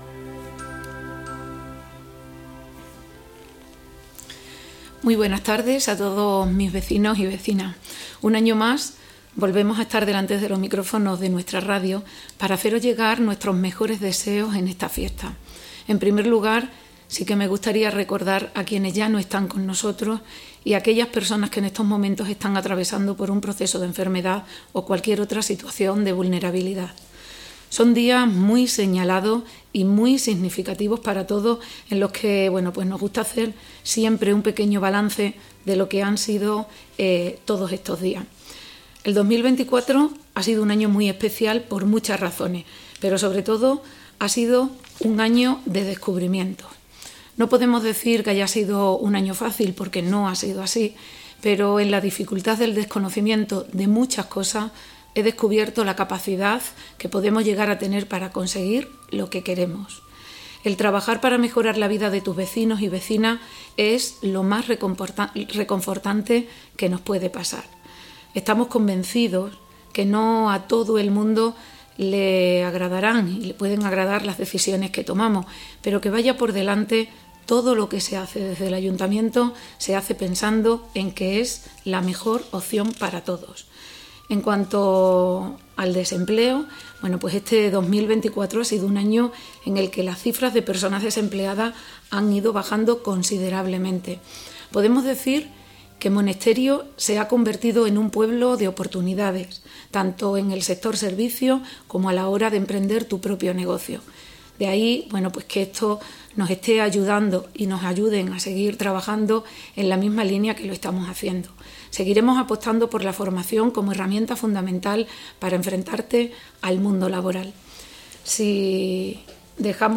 En su mensaje navideño la alcaldesa pone el acento en el empleo y en la reapertura de la mina de níquel
J7DtgMENSAJENAVIDADALCALDESA2024.mp3